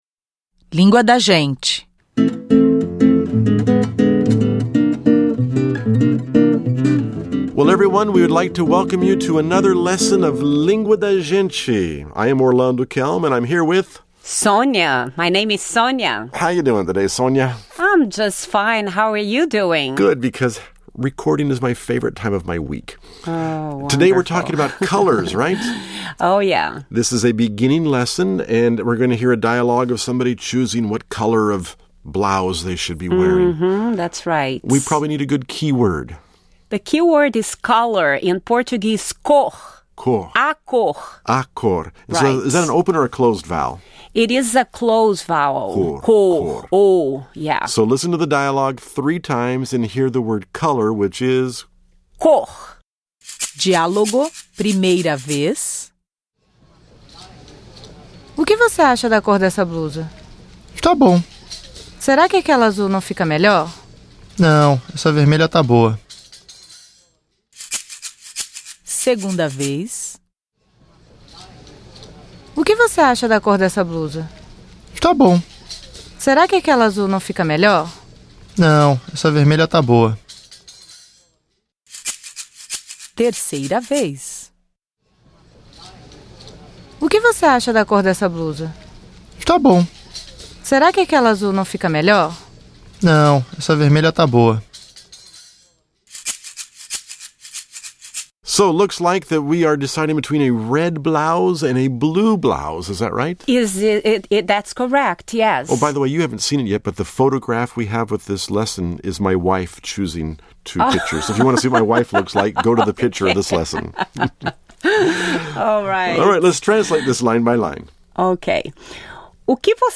However, we still need to learn how to handle this situation in Portuguese, and that is the objective of today’s lesson. And, we should learn the names of some colors too.